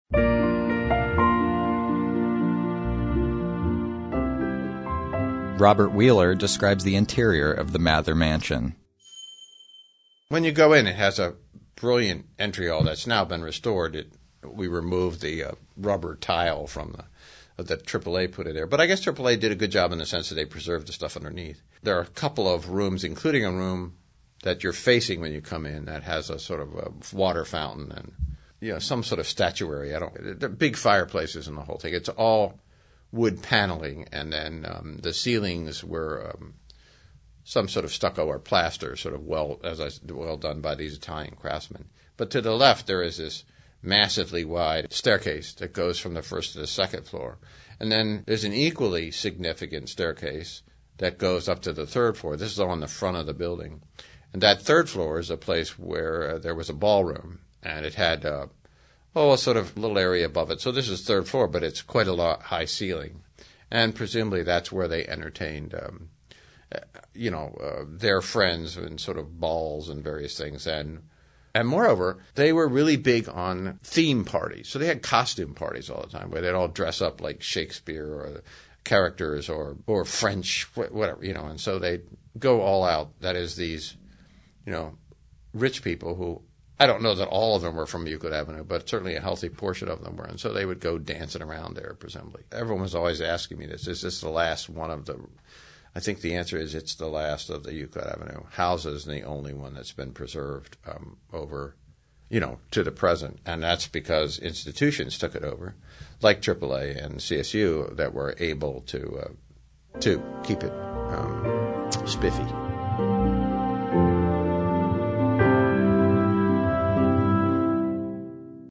Source: Cleveland Regional Oral History Collection